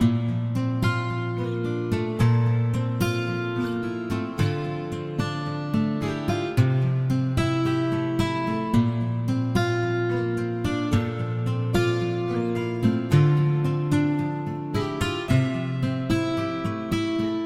Tag: 110 bpm Trap Loops Guitar Acoustic Loops 2.94 MB wav Key : Dm Audition